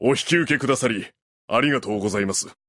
Menu Voice Lines